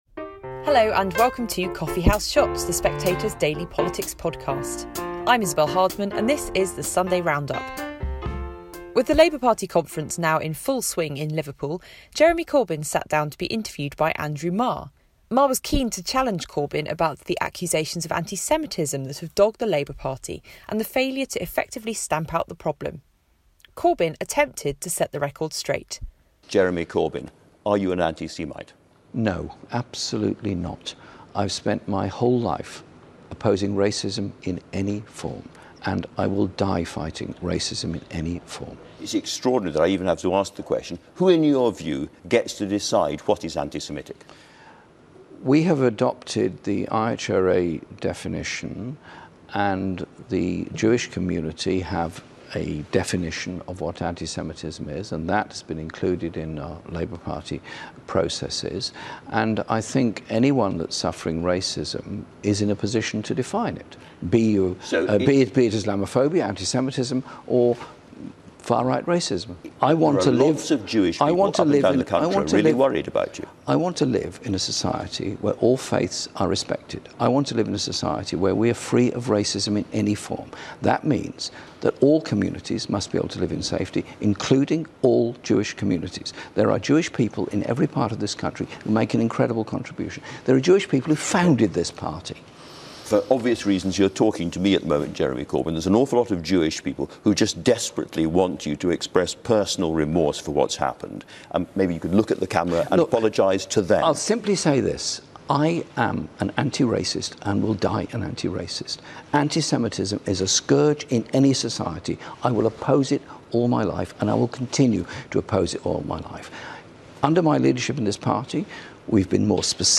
Isabel Hardman presents the highlights from Sunday's political interviews. Today's best moments come from Jeremy Corbyn, Dominic Raab, Nicky Morgan, Tom Watson and Lisa Nandy.